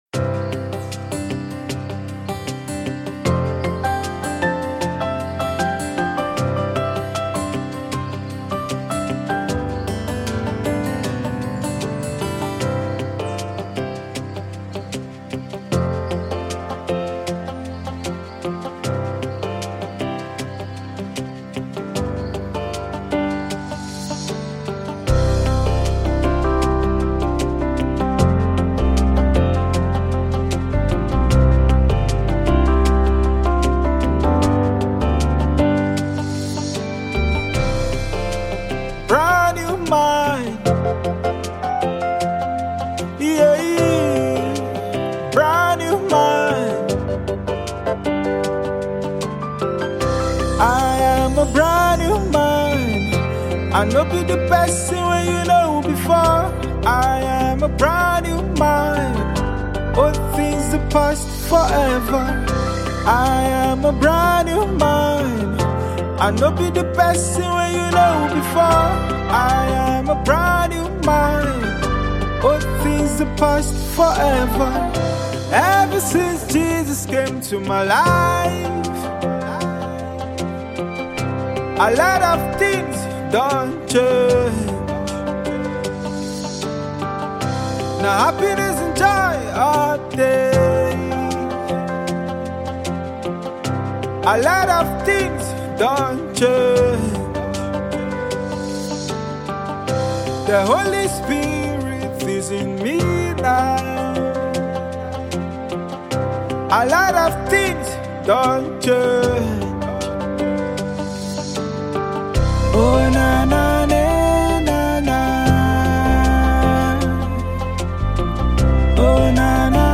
soul-stirring anthem of hope and transformation
With emotional lyrics and uplifting melodies